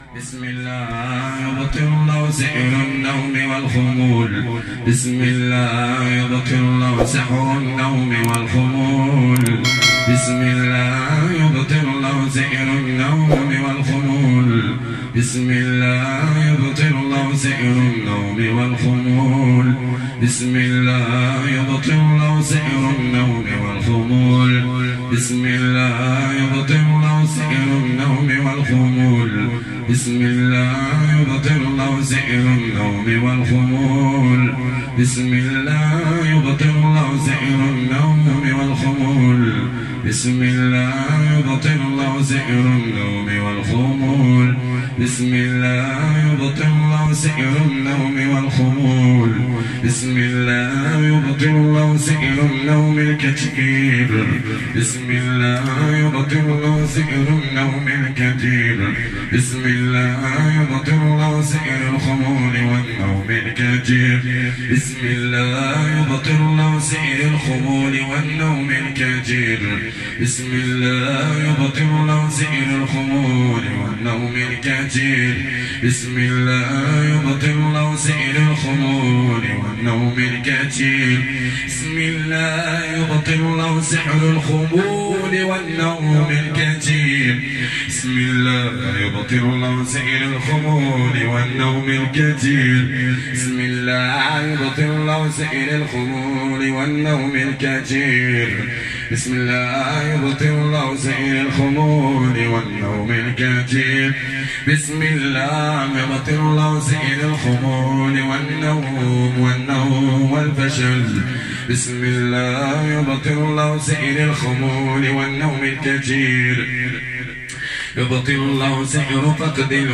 অতিরিক্ত ঘুম ও অলসতা দূর করার রুক‌ইয়াহ